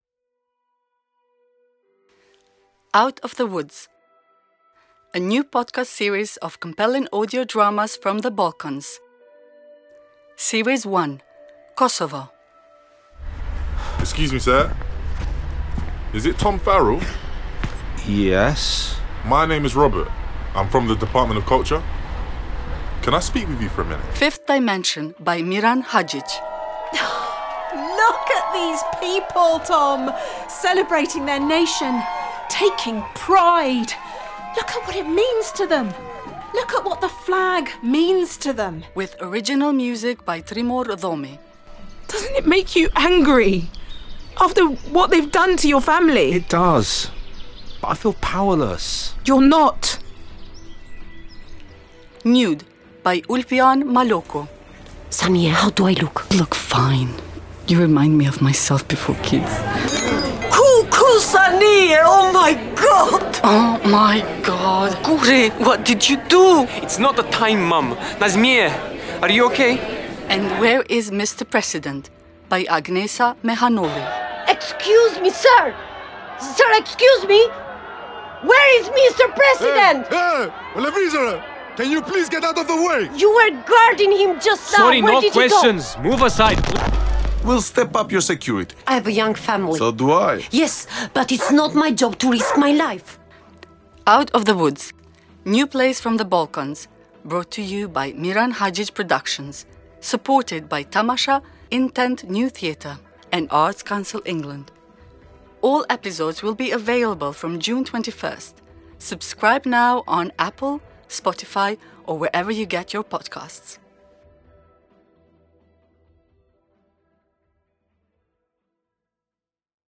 Here is a fantastic trailer, a taster of the new audio dramas: